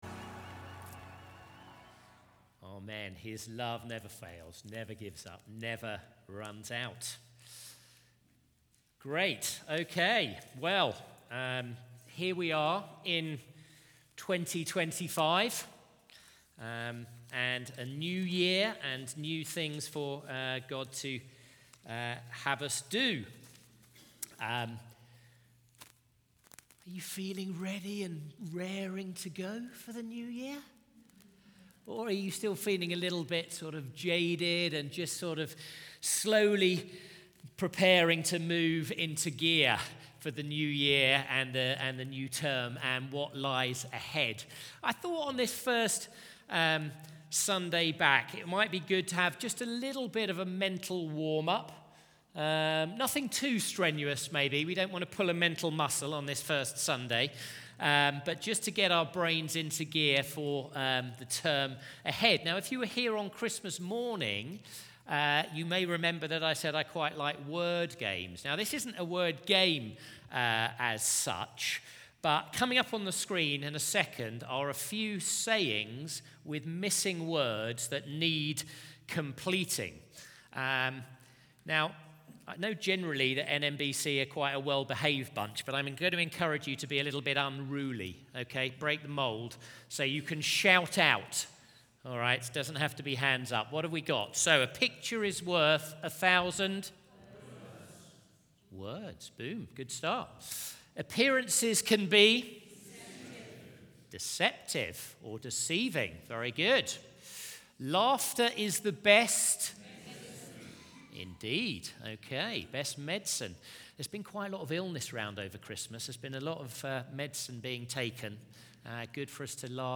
Media for Sunday Service on Sun 05th Jan 2025 10:30 Speaker
Sermon Sermon Slides Open Small Group Notes Open HINT: Try searching for part of a speaker's name, bible reference or series title.